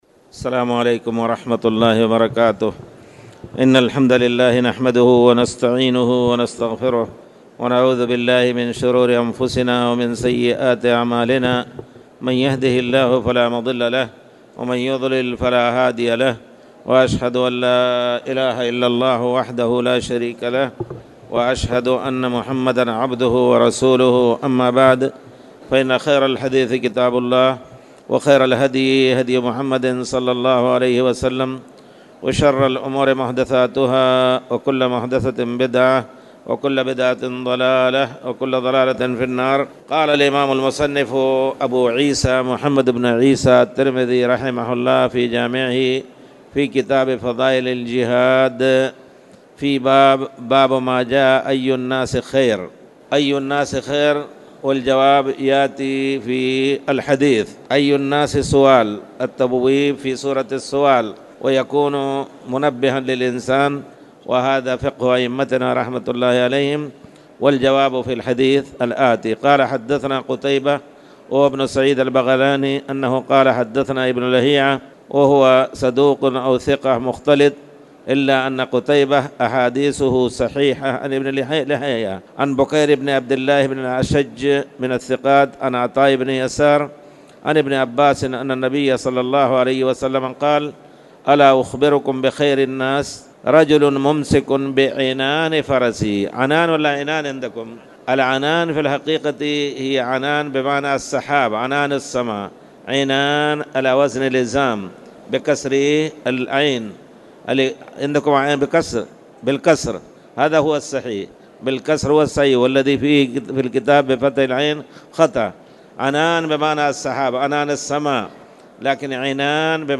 تاريخ النشر ٣ شعبان ١٤٣٨ هـ المكان: المسجد الحرام الشيخ